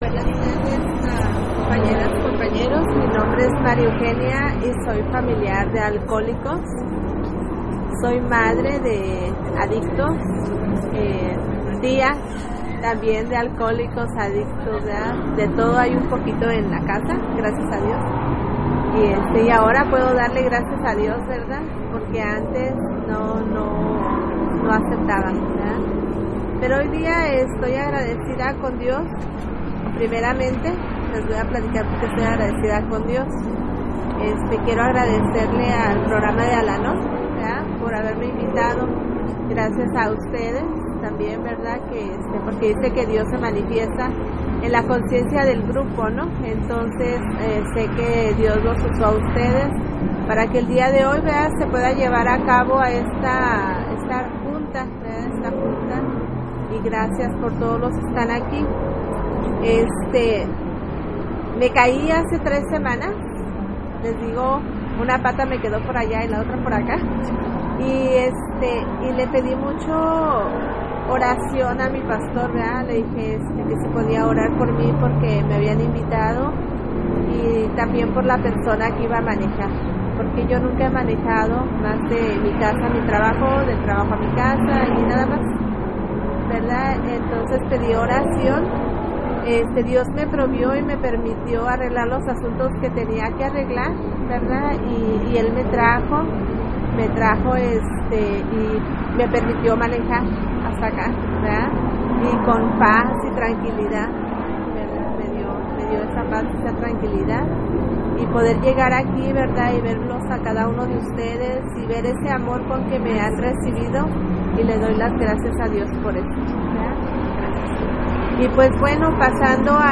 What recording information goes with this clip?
36th Annual Serenity By The Sea